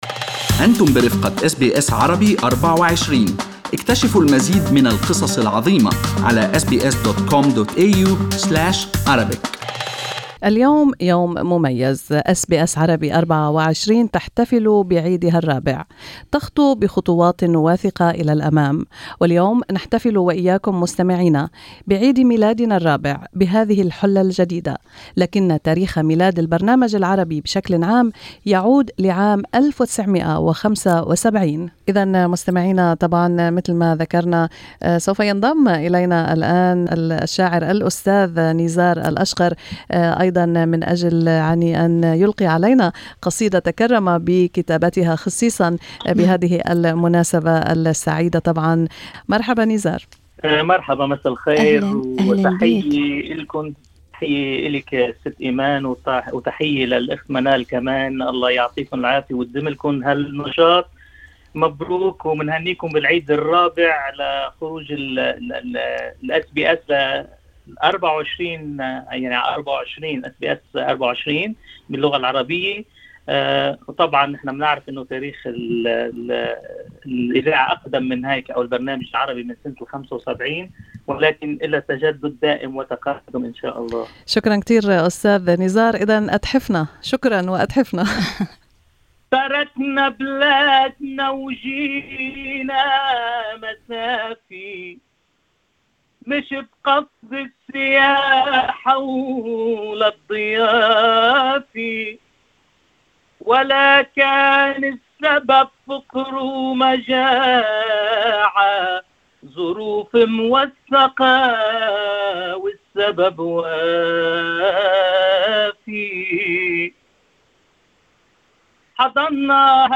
قصيدة زجلية